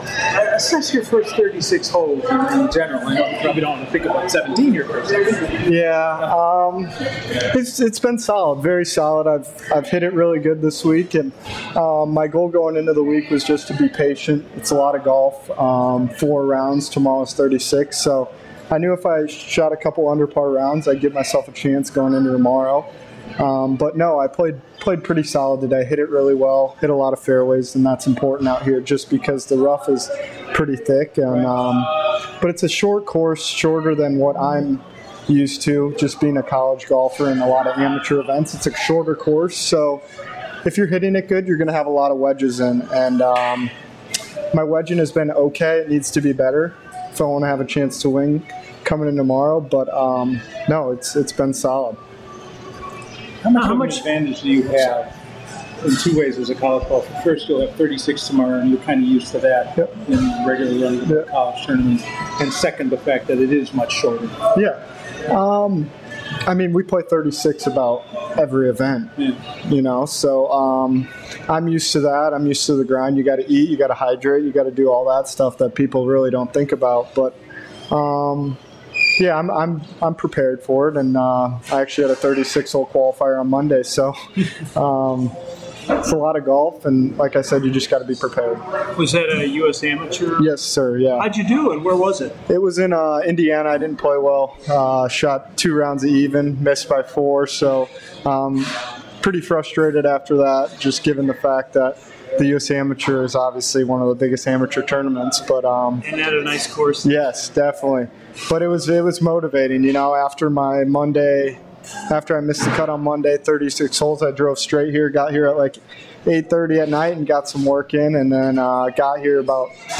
full interview
at the 2021 Illinois State Amateur at Mistwood